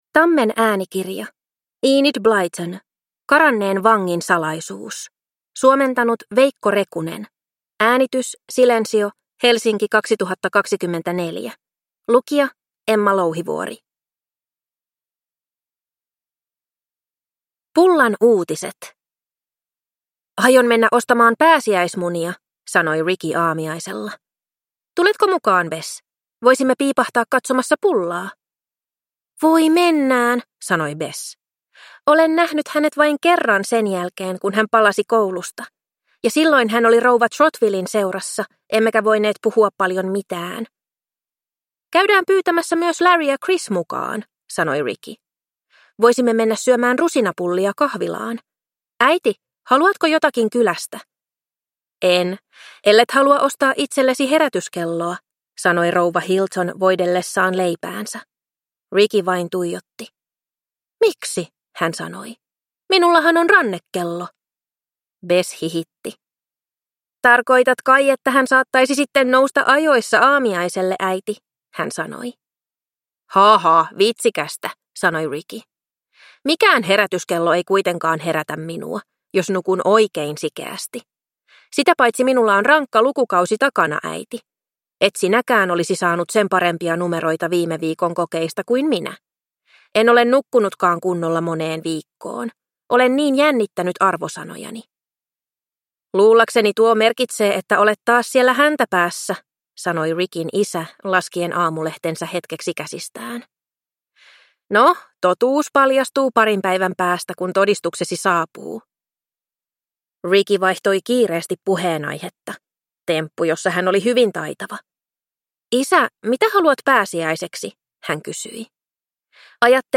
Karanneen vangin salaisuus – Ljudbok